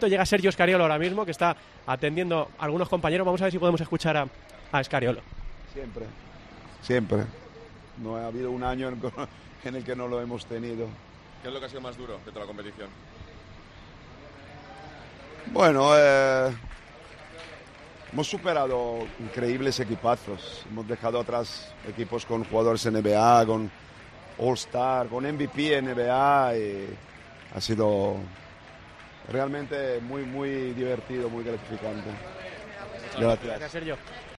El seleccionador nacional atiende a los medios a su llegada al hotel de Madrid.